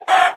mob / chicken / hurt2.ogg
hurt2.ogg